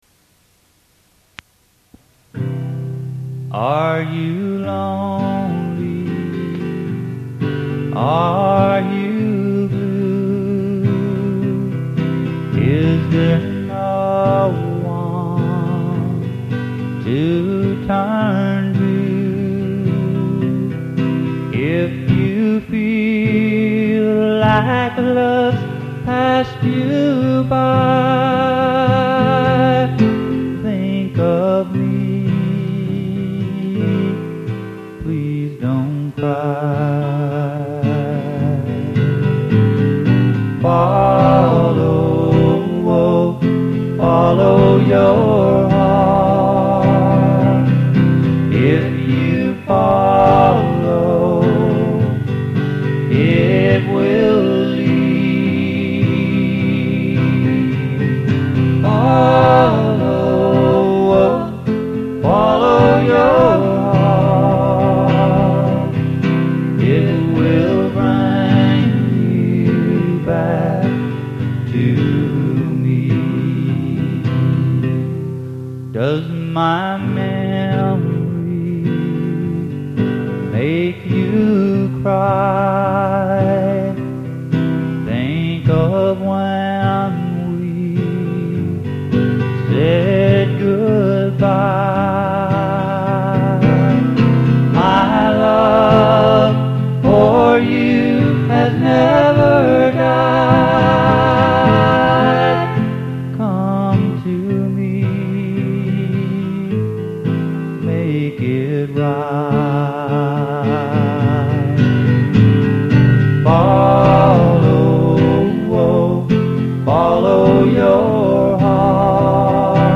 4 TRACK DEMO